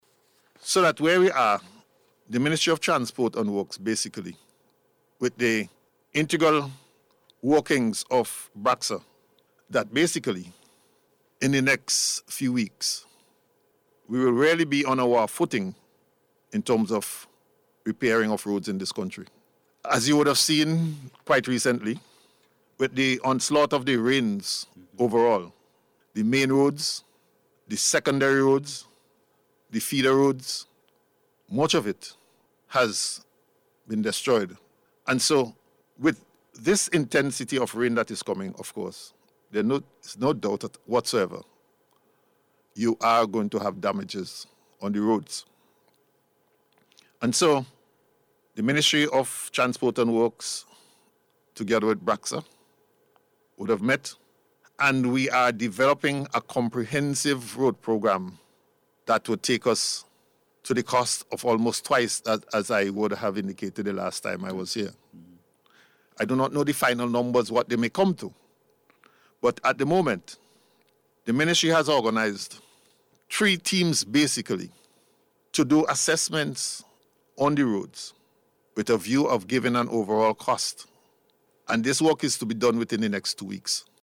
This was disclosed by Acting Prime Minister Montgomery Daniel, on NBC Radio this morning.